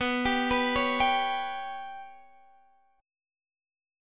Bsus2#5 chord